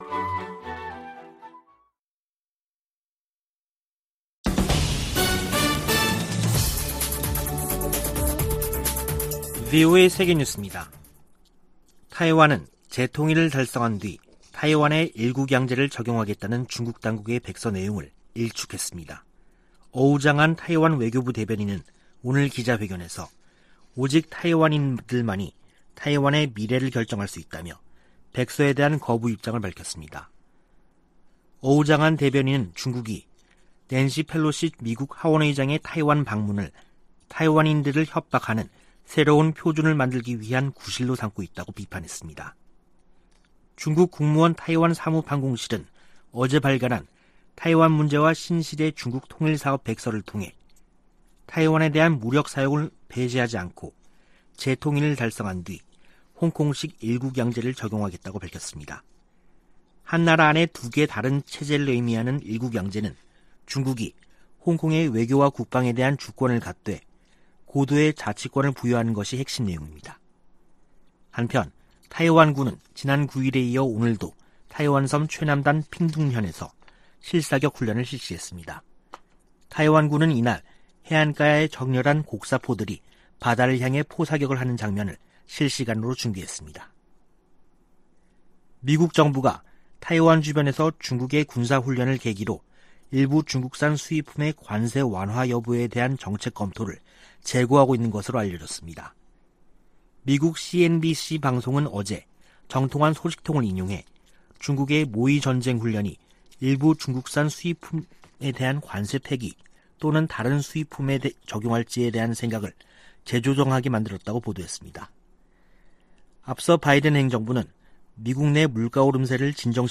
VOA 한국어 간판 뉴스 프로그램 '뉴스 투데이', 2022년 8월 11일 2부 방송입니다. 미 국무부는 중국의 사드와 관련한 한국에 대한 이른바 3불1한 주장은 부적절하다고 지적했습니다. 한국 대통령실 측은 사드는 북한 핵과 미사일로부터 국민을 지키기 위한 자위 방어 수단이라며 협의 대상이 결코 아니라고 강조했습니다. 북한의 거듭된 탄도미사일 발사가 한반도의 긴장을 고조시키고 있다고 아세안지역안보포럼 외교장관들이 지적했습니다.